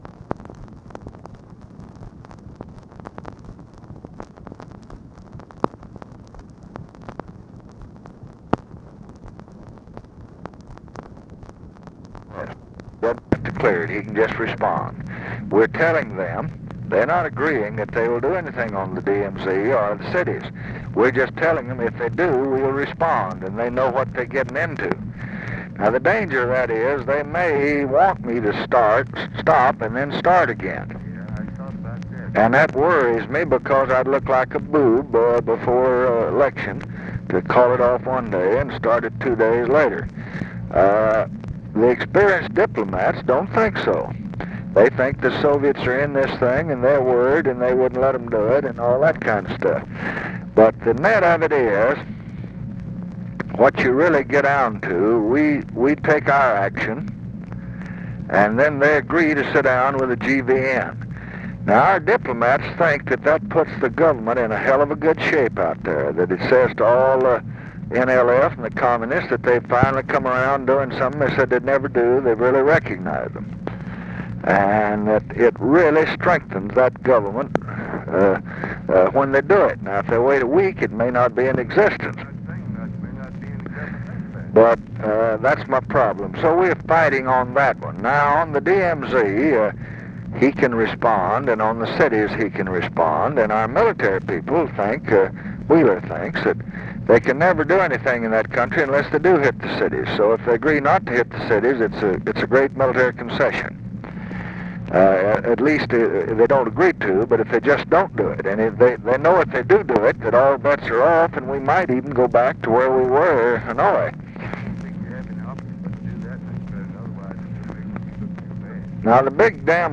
Conversation with RICHARD RUSSELL, October 23, 1968
Secret White House Tapes